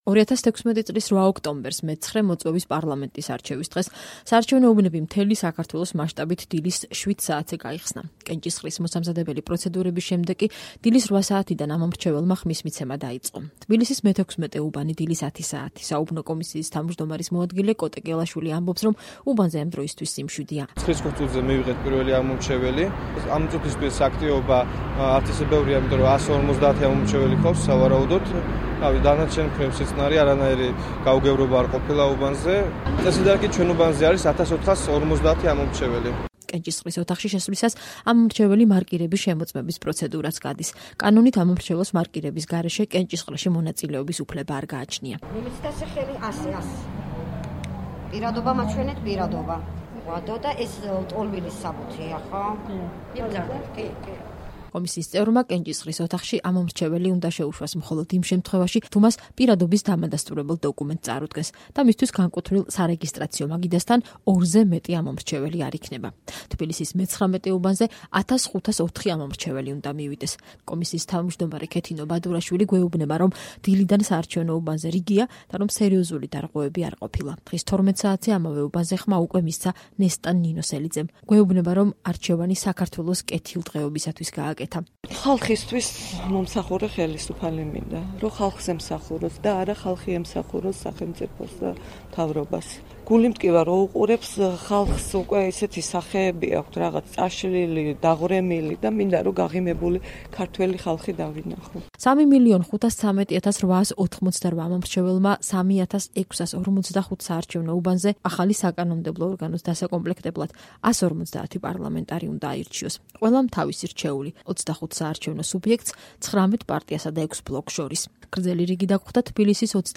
2016 წლის 8 ოქტომბერს, მე-9 მოწვევის პარლამენტის არჩევის დღეს, საარჩევნო უბნები, მთელი საქართველოს მასშტაბით, დილის 07:00 საათზე გაიხსნა, კენჭისყრის მოსამზადებელი პროცედურების შემდეგ კი, დილის 08:00 საათიდან, ამომრჩეველმა ხმის მიცემა დაიწყო. თბილისის მე-16 უბანი, დილის 10 საათი.